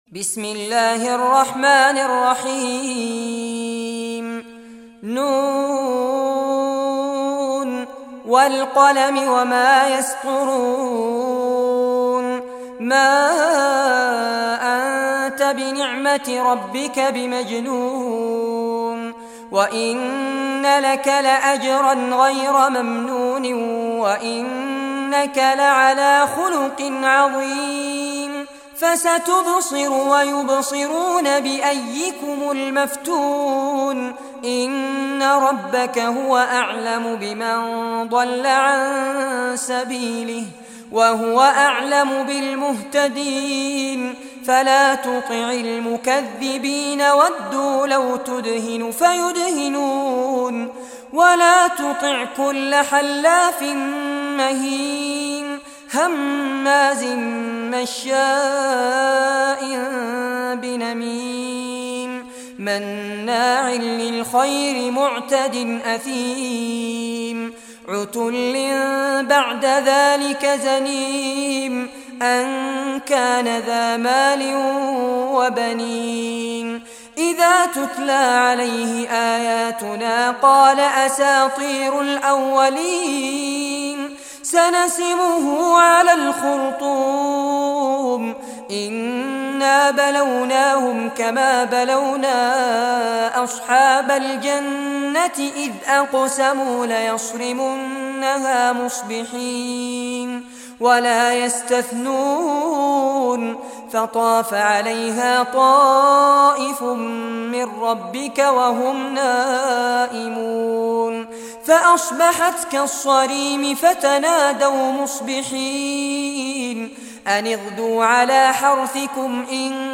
Surah Al-Qalam Recitation by Sheikh Fares Abbad
Surah Al-Qalam, listen or play online mp3 tilawat / recitation in Arabic in the beautiful voice of Sheikh Fares Abbad.
68-surah-qalam.mp3